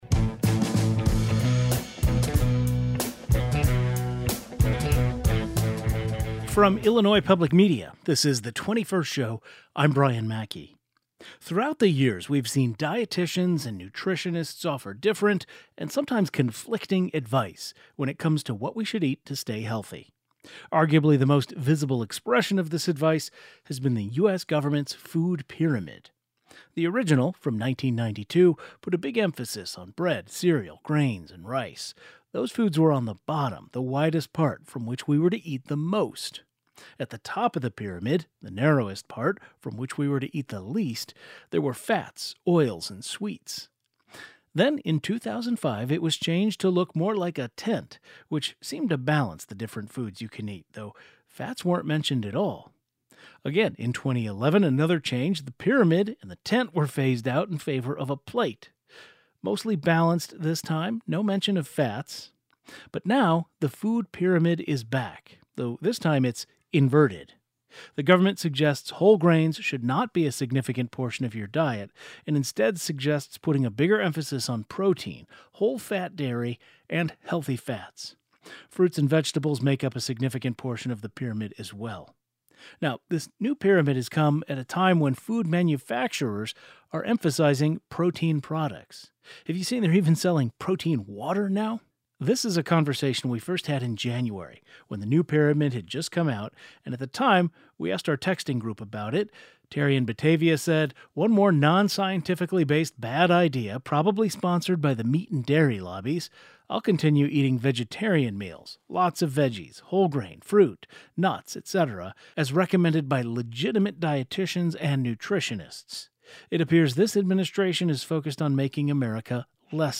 The current Food Pyramid is now inverted. Two nutrition experts discuss present day nutrition guidelines.
Today's show included a rebroadcast of the following "best of" segment first aired January 23, 2026: The new Food Pyramid and constantly changing dietary guidelines.